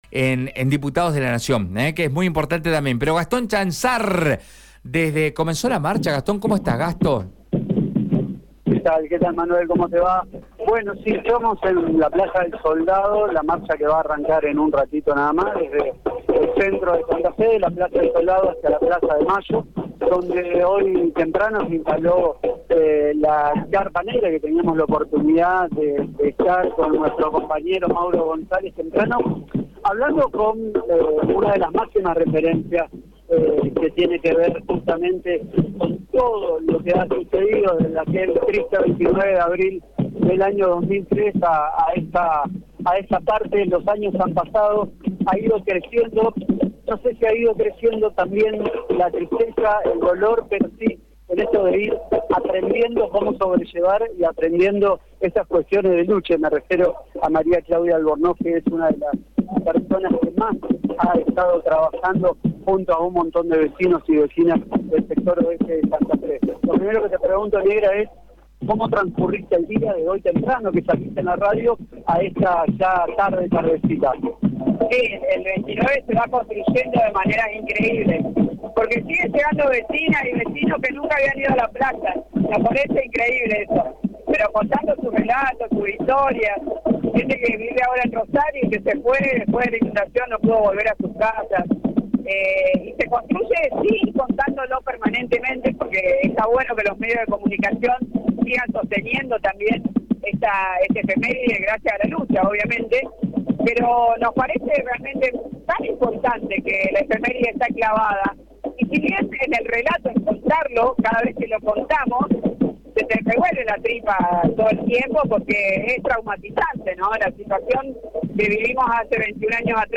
A 21 años de la inundación de Santa Fe, vecinos y vecinas de la ciudad realizaron la tradicional marcha desde la Plaza del Soldado hacia Casa de Gobierno.